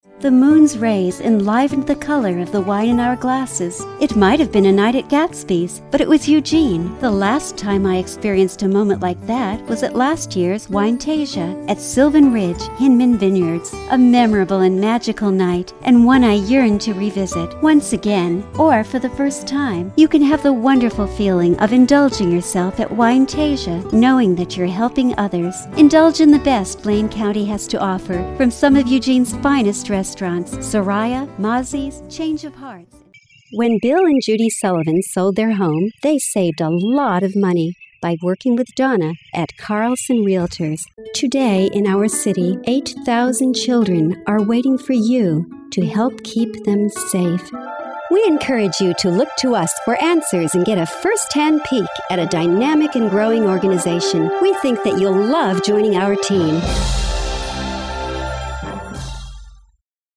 warm, clear diction, professional, expressive, engaging young adult, middle age, senior E-Learningk, Medical, Technical, Corporate training, educational, promotions, business, websites, audio books, children\'s stories, IVR, English language learning, real estate, travel logs narrations
mid-atlantic
Sprechprobe: Werbung (Muttersprache):